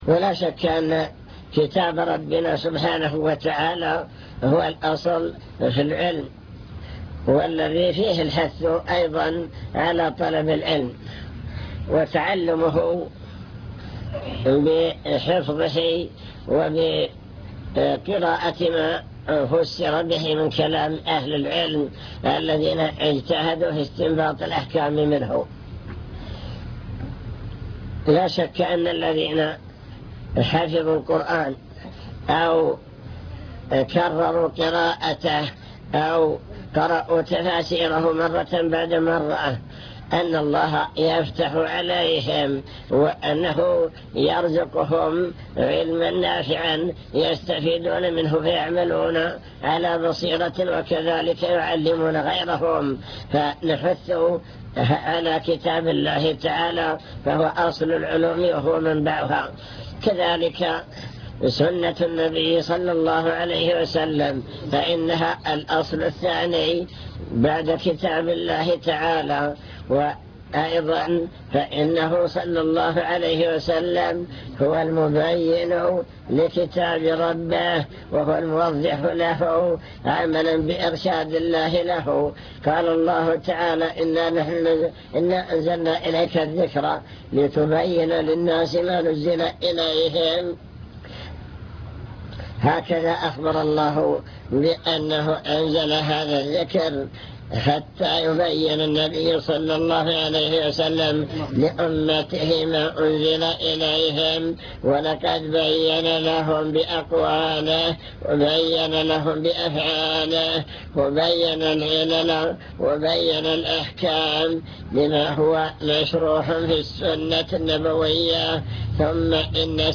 المكتبة الصوتية  تسجيلات - محاضرات ودروس  محاضرات عن طلب العلم وفضل العلماء بحث في: أهم المسائل التي يجب على المسلم تعلمها